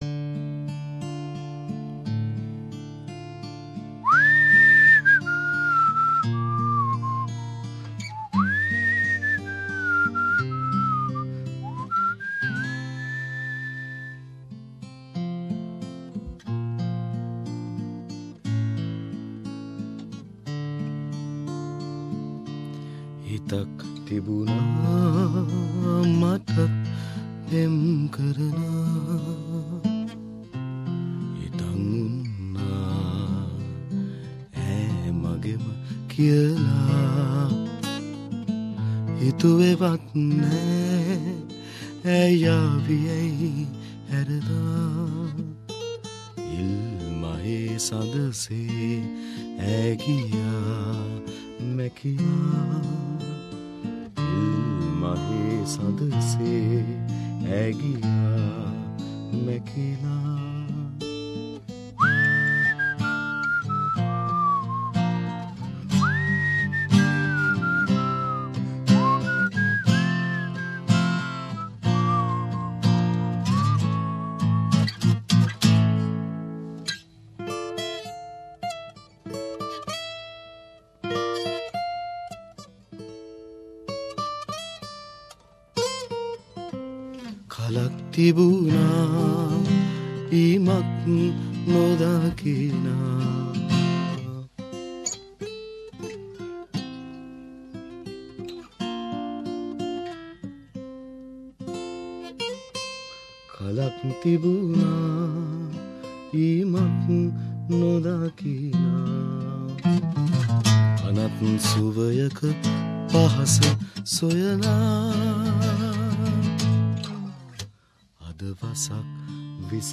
Two Sri Lankan musicians
at SBS studios in Melbourne